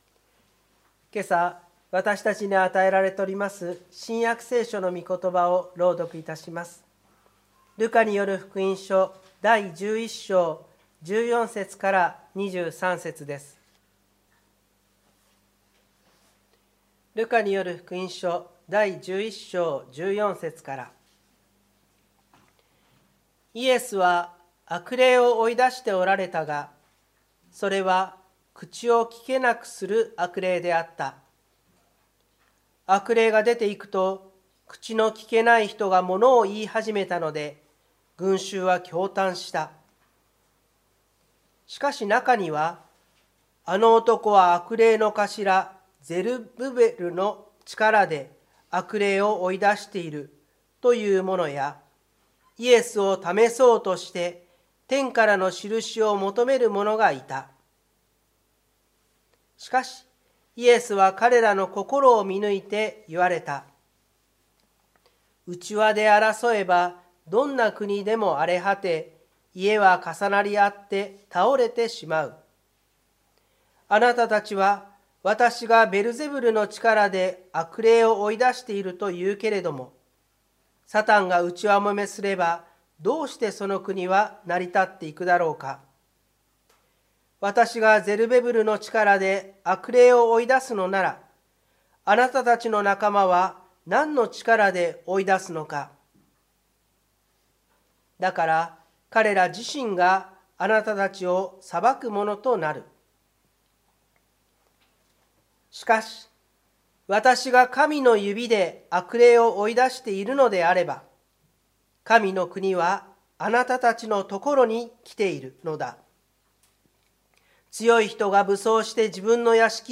湖北台教会の礼拝説教アーカイブ。
日曜朝の礼拝